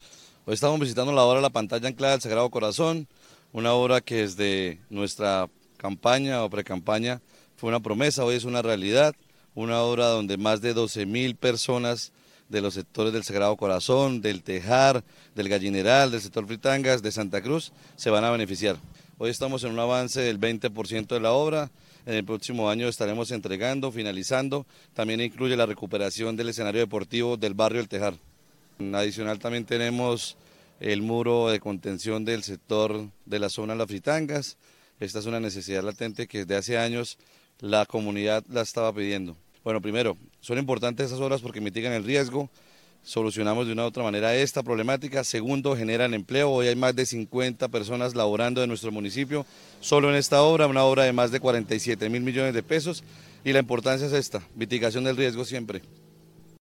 Carlos Román - Alcalde de Girón.mp3